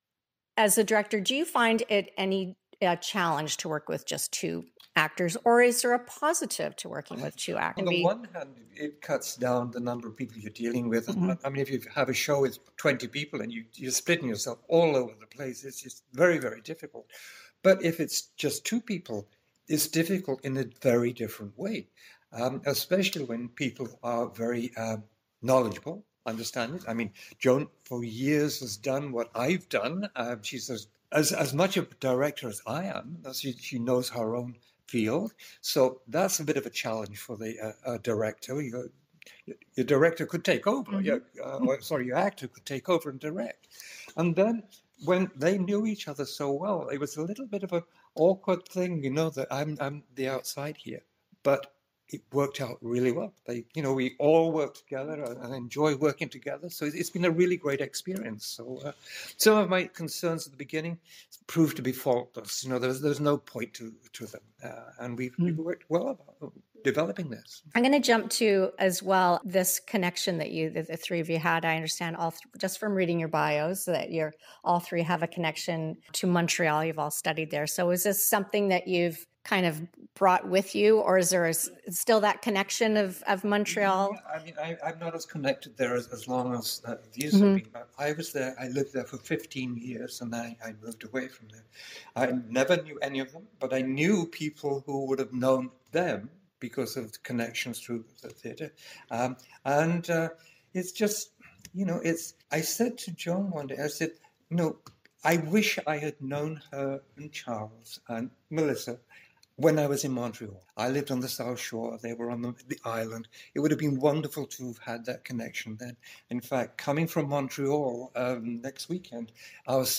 Interview Part 1 Interview Part 2
Collected-Stories-993-Interview-p1.mp3